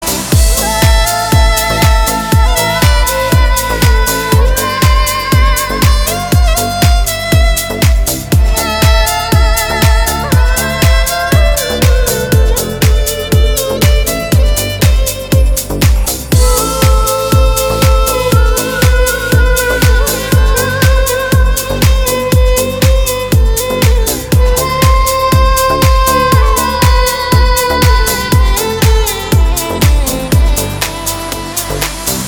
• Качество: 320, Stereo
deep house
восточные мотивы
атмосферные
скрипка
красивый женский голос
Deep House со звуками скрипки и красивым женским вокалом.